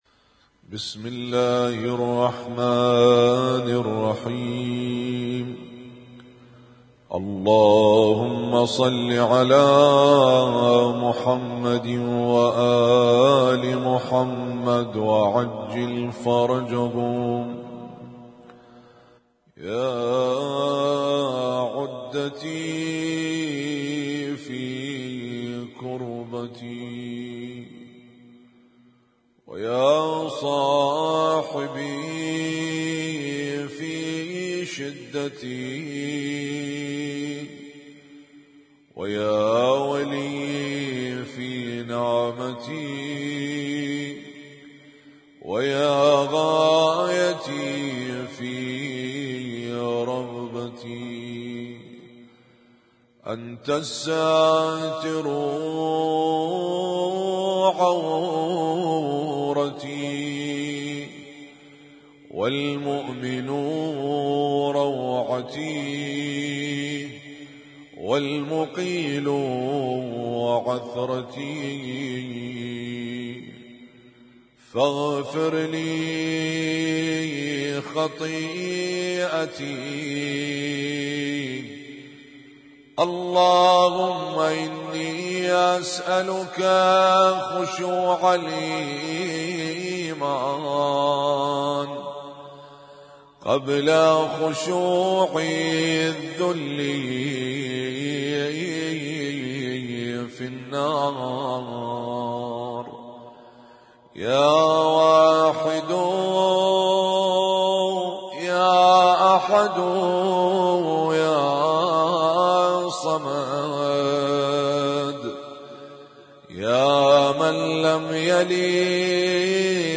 Husainyt Alnoor Rumaithiya Kuwait
اسم التصنيف: المـكتبة الصــوتيه >> الادعية >> الادعية المتنوعة